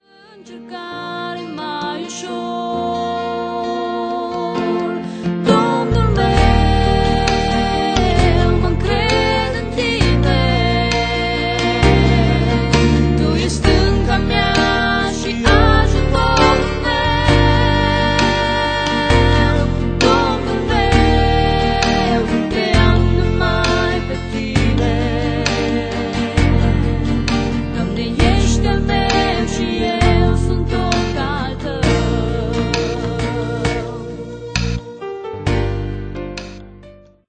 si vocile de exceptie.